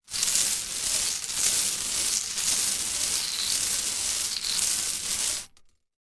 bingo sounds
ball_roll_3.ogg